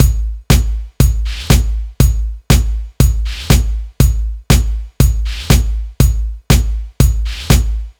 Ew Bd _ Snr.wav